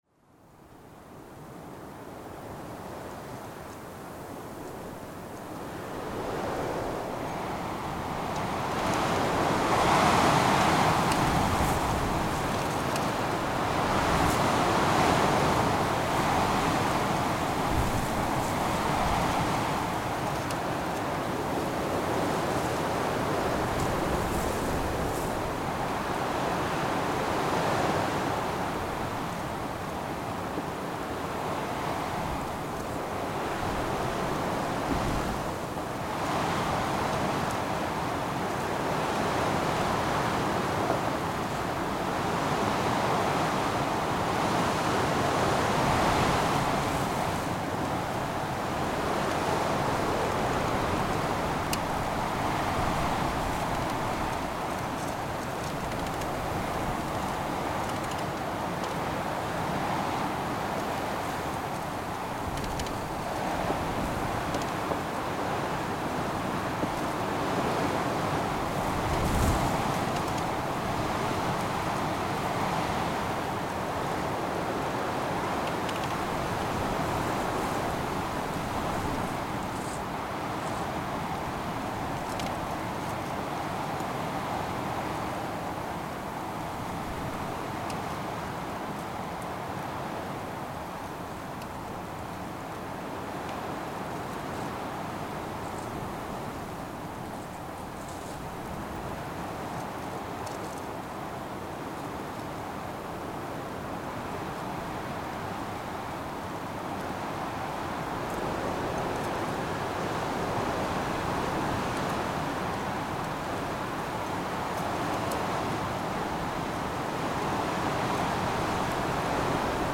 Skrifað í Náttúra | Merkt Ísafjörður, Óveður, Kirkjugarður, Korg MR1000, Rode Blimp, Sennheiser ME64, Skutulsfjörður, Snjór, Veður, Vestfirðir, Vindur | 5 athugasemdir
Ég gerði mér þó ferð að kirkjugarðinum í Engidal við Skutulsfjörð þar sem finna mátti nokkurt safn af krossum á leiðum kristinna manna.
Gekk á með hvössum, dimmum hríðarbyljum og skafrenningi.
Tekið var upp á Korg MR1000 í 24bit/192Khz. Hljóðnemar voru Sennheiser ME64 í Rode Blimp vindhlífum sem voru í u.þ.b. 80°. Helst heyrist í vindinum sem leikur um runnahríslur garðsins. Borði á kransi blaktir á nýtekinni gröf og strengur klappar fánastöng í fjarska. Þá sígur hljóðmaður öðru hvoru hor í nös þar sem hann hírist skjálfandi bak við húsvegg kapellunar í garðinum. Það sem líklega heyrist aðeins sem lágvært suð hér á netútgáfu þessarar upptöku eru snjókornin sem strjúkast við snjóbreiðuna í skafrenningnum.